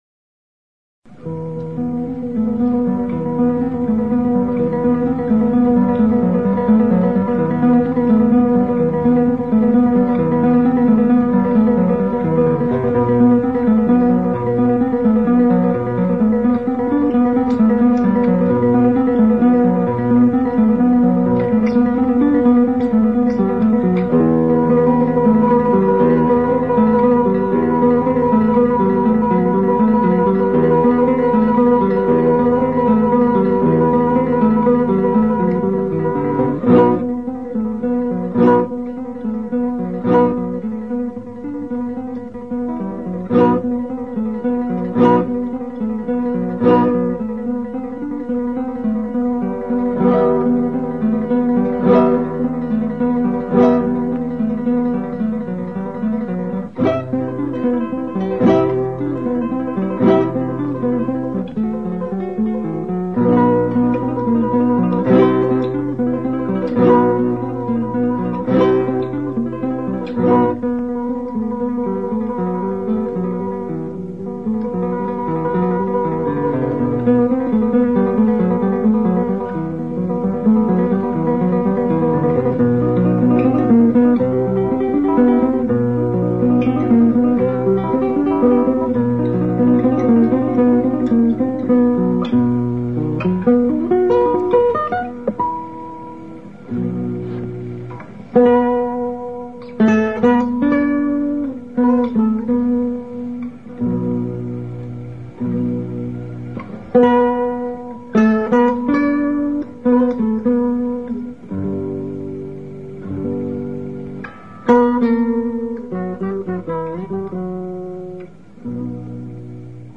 Classical Guitar
Classical Guitar Kresge Little Theater MIT Cambridge, Massachusetts USA March 22